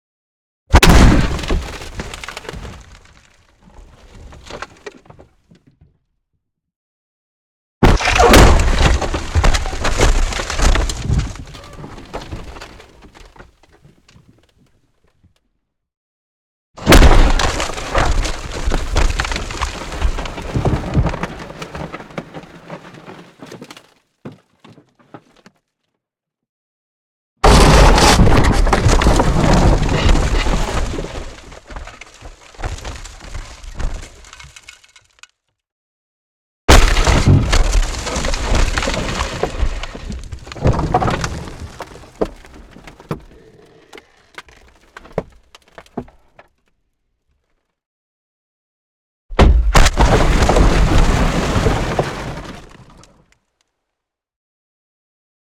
Pole Position - Car Destruction
Car_Destruction_Designed_t44_Impacts_Interor.ogg